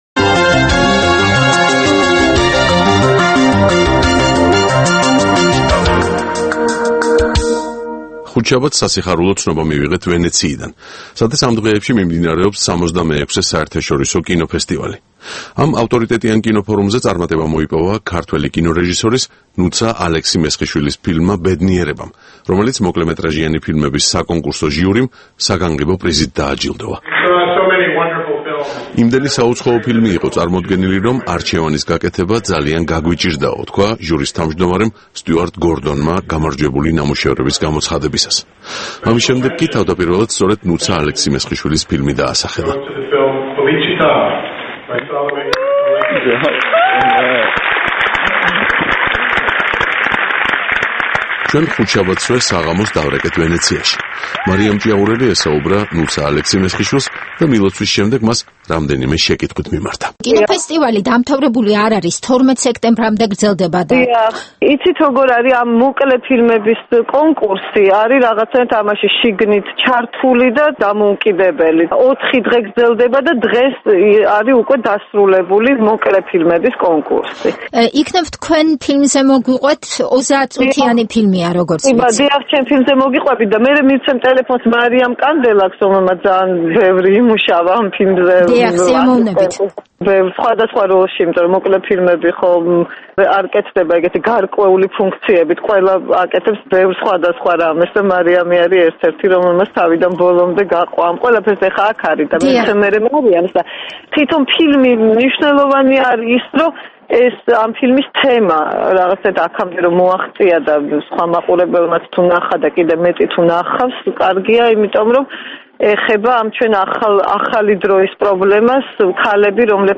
ინტერვიუ ფილმის შემქმნელებთან